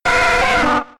Cri de Carapuce K.O. dans Pokémon X et Y.